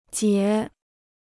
劫 (jié): to rob; to plunder.